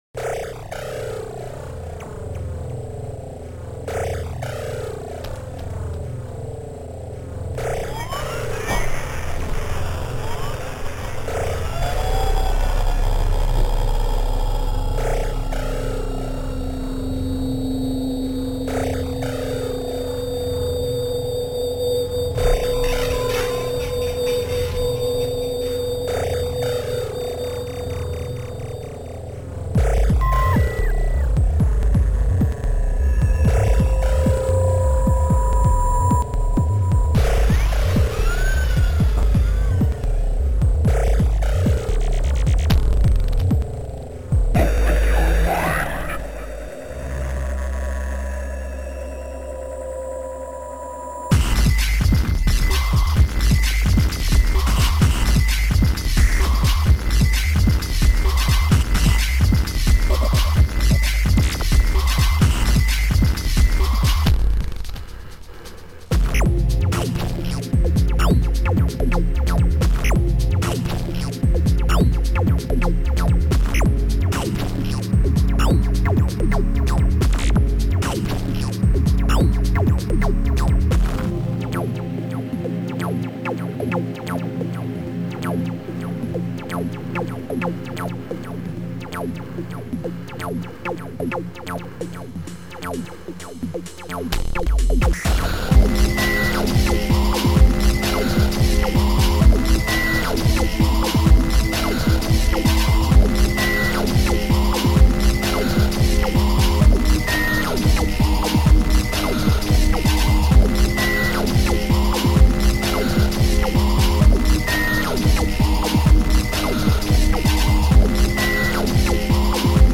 Sound Format: Noisetracker/Protracker
Channels:_4